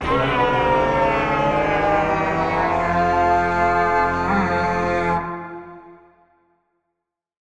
Звуки рогов в Майнкрафт
Goat_Horn_Call5.mp3